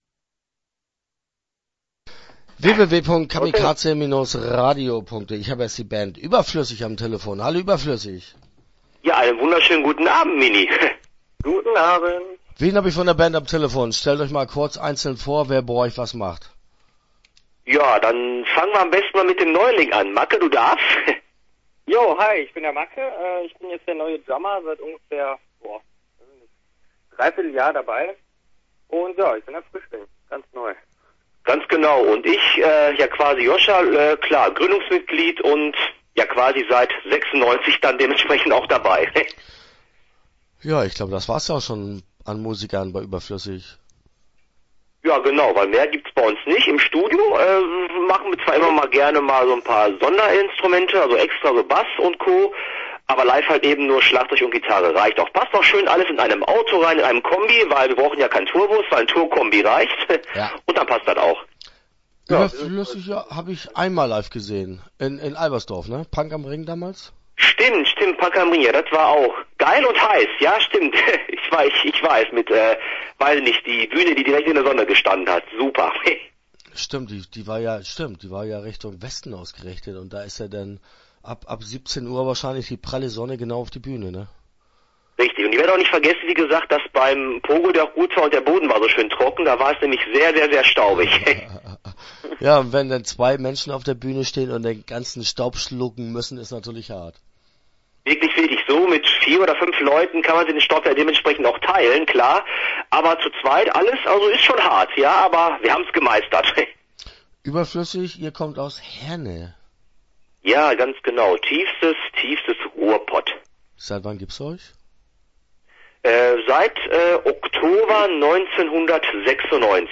berflüssig - Interview Teil 1 (14:31)